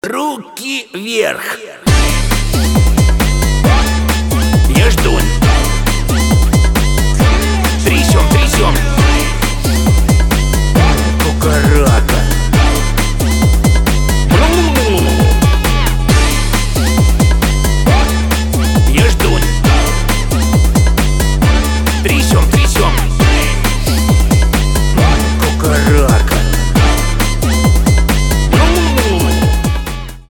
поп
битовые , басы , качающие